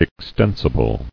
[ex·ten·si·ble]